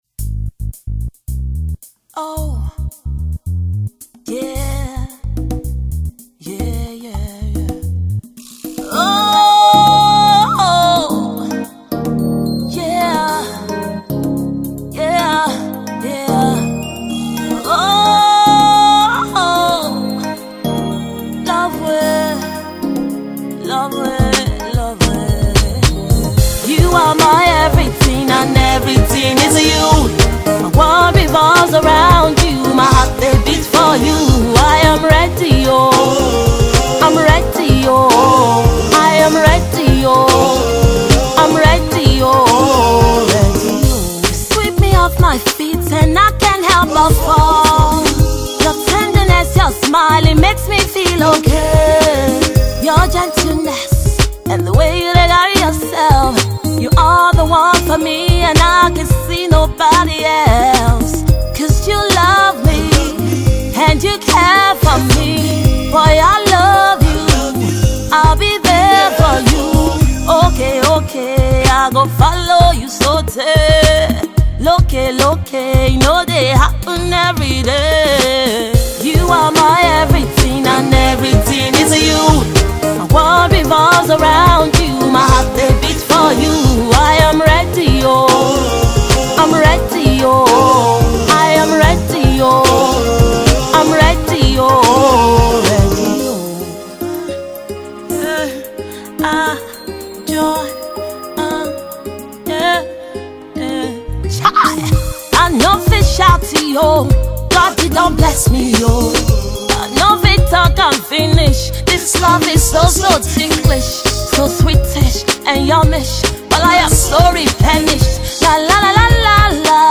atmospheric and ethereal sounds of Africa